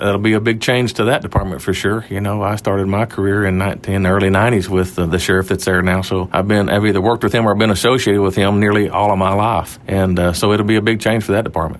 KTLO News caught up with the Judge to discuss the upcoming campaign and his motives for seeking re-election.